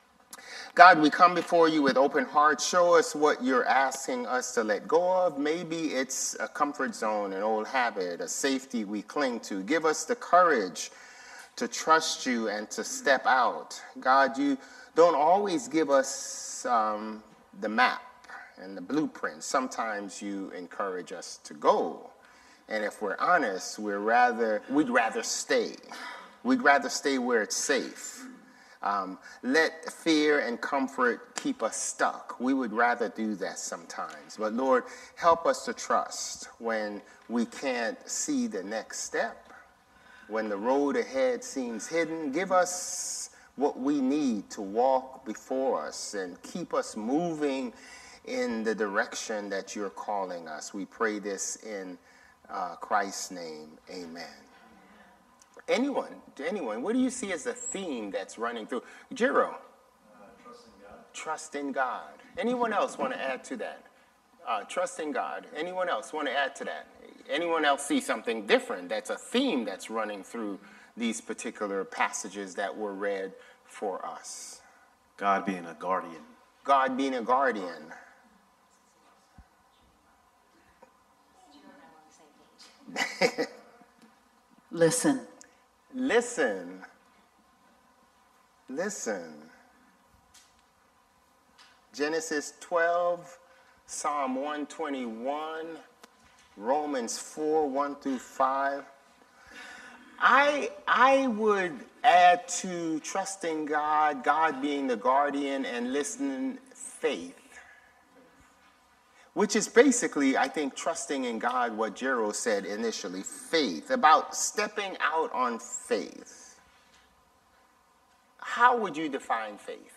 March 1 Worship
The conversation beautifully weaves together Psalm 121's promise of divine guardianship with Romans 4's revolutionary teaching on justification by faith rather than works.